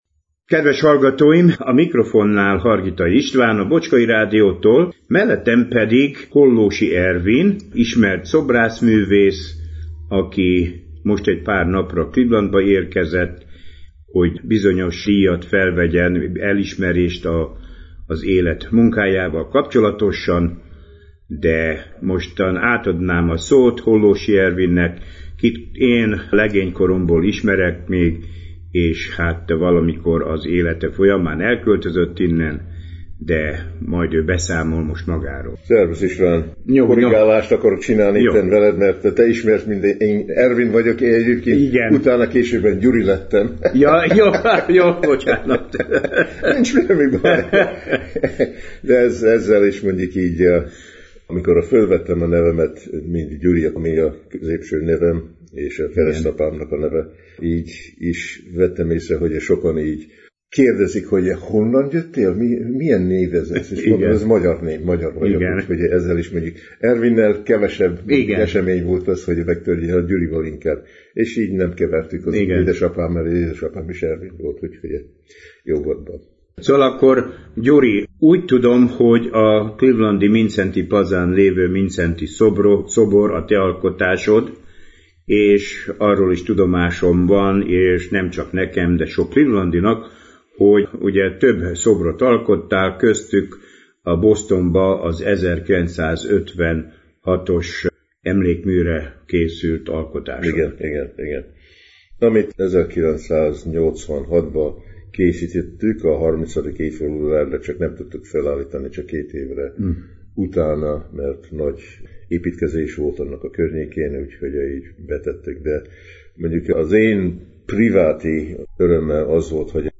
Múlt hónap szeptember 20-án alkalom nyílt egy interjút készíteni